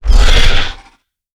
Growl2.wav